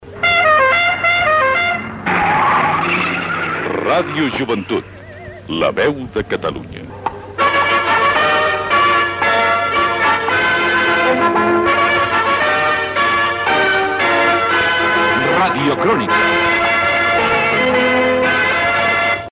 Indicatiu de l'emissora, identificació del programa.